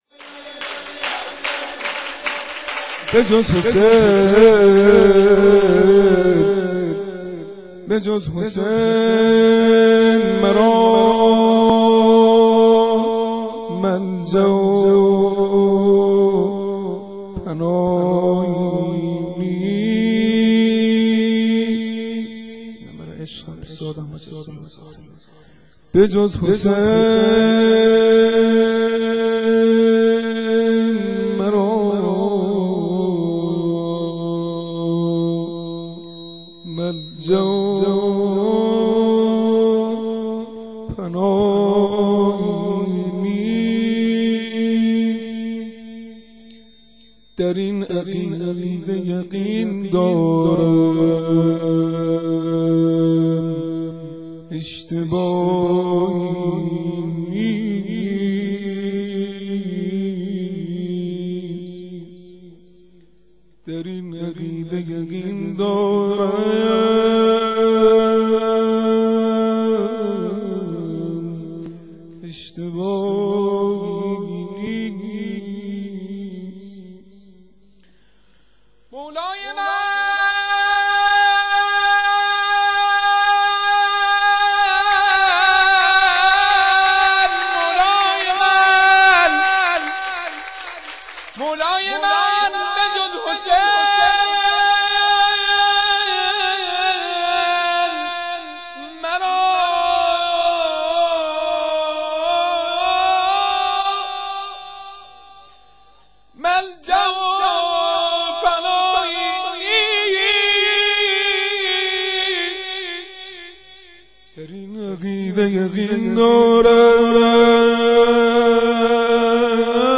شام میلاد حضرت علی اکبر 1392